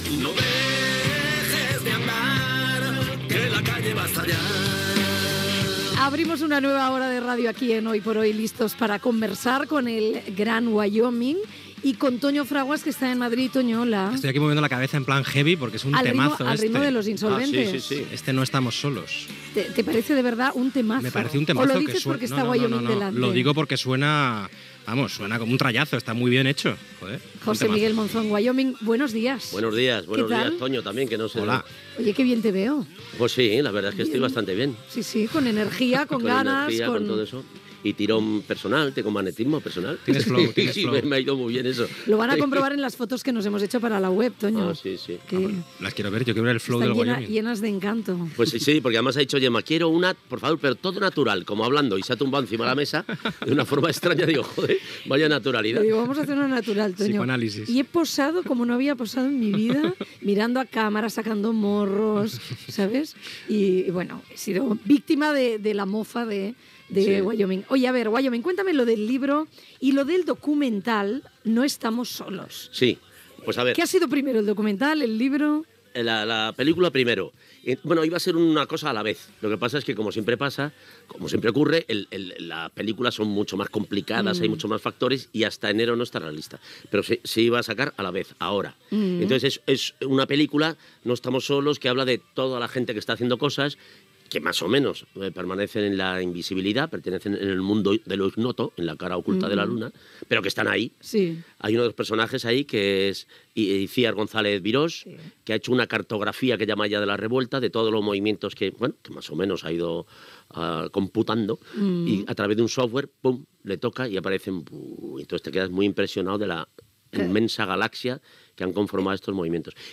Entrevista al Gran Wyoming (José Miguel Monzón) que presenta el seu llibre "No estamos solos"
Info-entreteniment